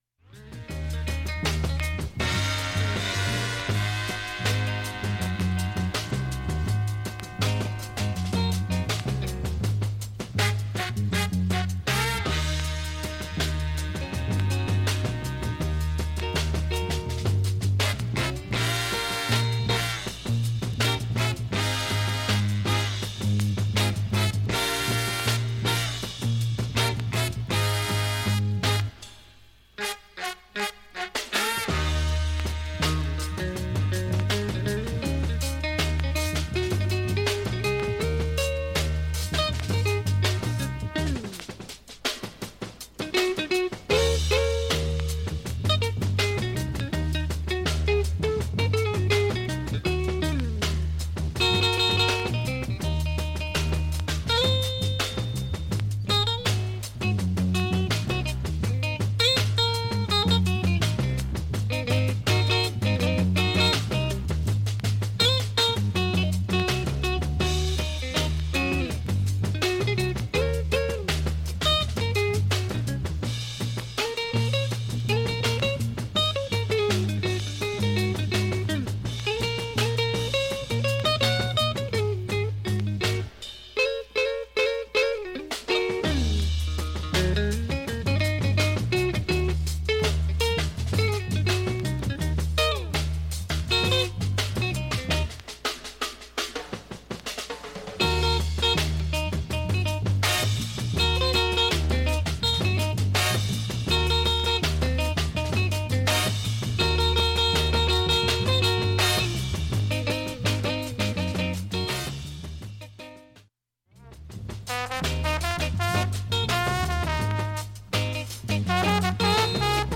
盤面きれいで音質良好全曲試聴済み。
３回までのかすかなプツが１箇所
単発のかすかなプツが８箇所
インストルメンタルアルバム作品。